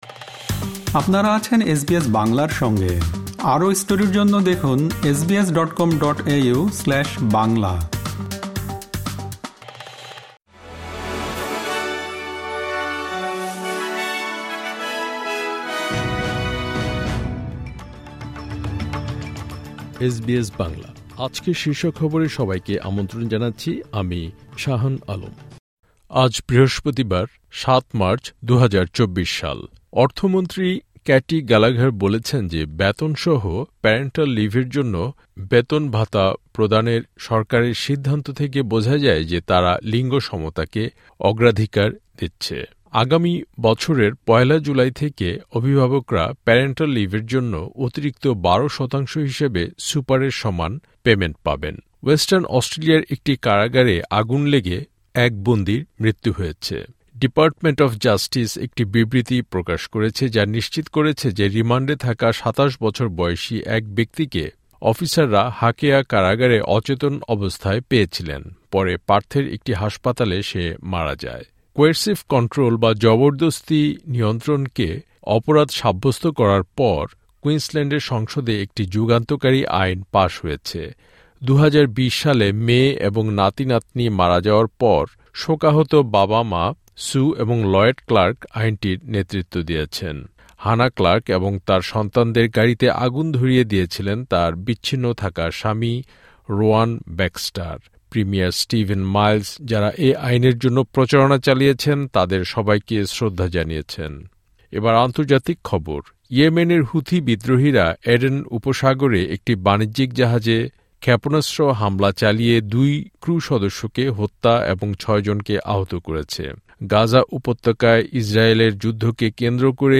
এসবিএস বাংলা শীর্ষ খবর: ৭ মার্চ, ২০২৪